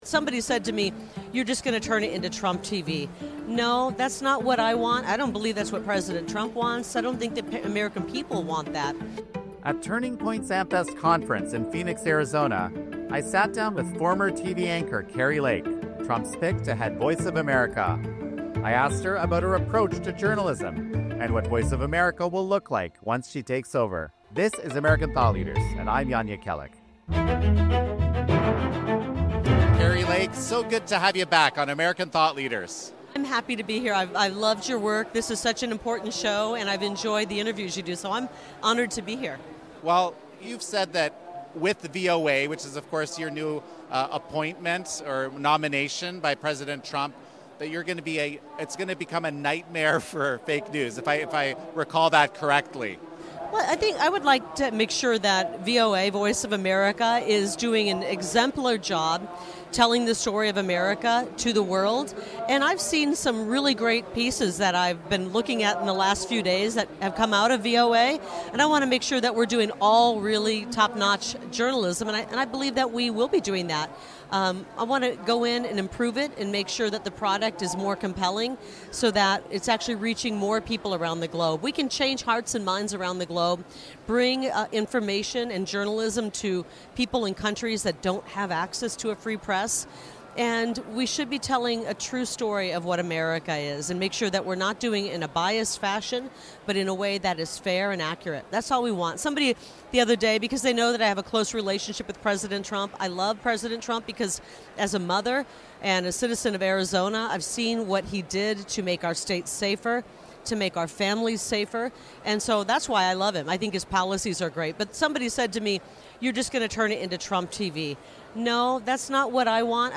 [RUSH TRANSCRIPT BELOW] At Turning Point USA’s AmFest conference in Phoenix, I sat down with former TV anchor Kari Lake, President-elect Donald Trump’s pick to head Voice of America (VOA).